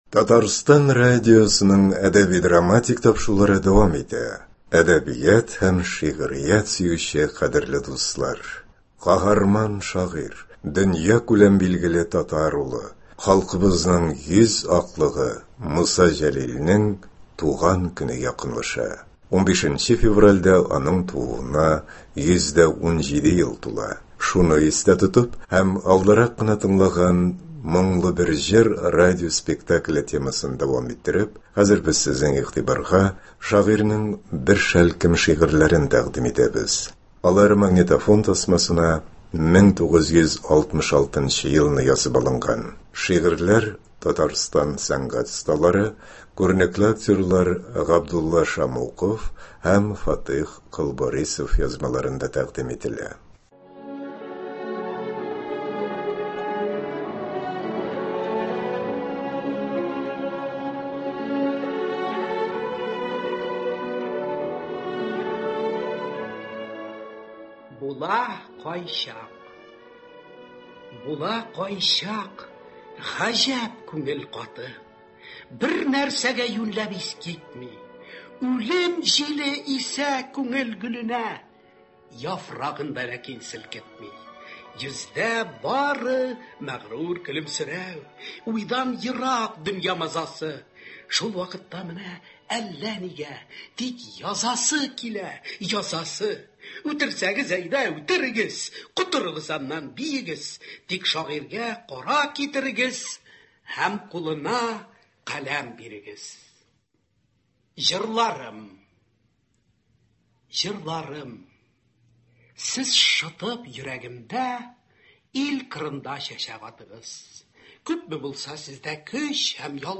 Хәзер без сезнең игътибарга шагыйрьнең бер шәлкем шигырьләрен тәкъдим итәбез. Алар магнитофон тасмасына 1966 елны язып алынган.